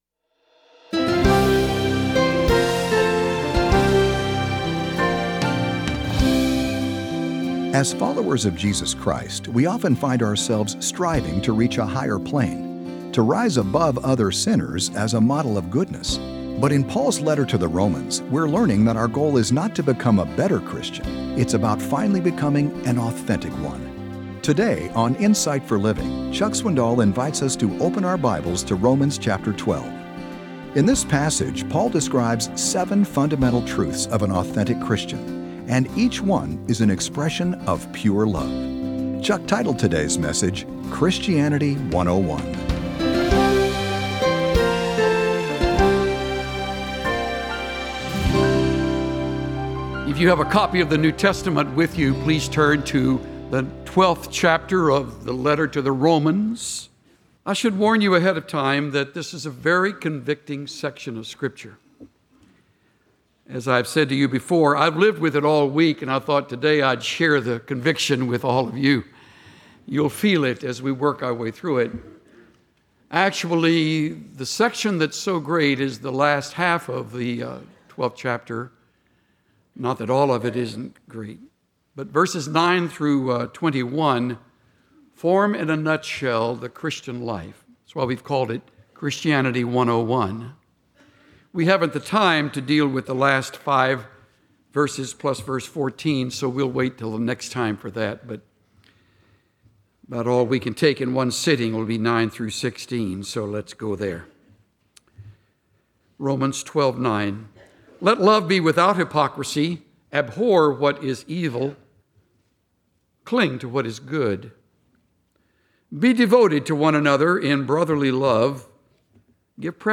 Join the millions who listen to the lively messages of Chuck Swindoll, a down-to-earth pastor who communicates God’s truth in understandable and practical terms—with a good dose of humor thrown in. Chuck’s messages help you apply the Bible to your own life.